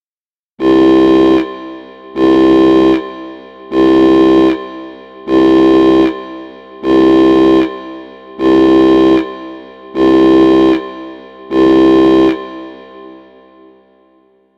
Звуки охранной сигнализации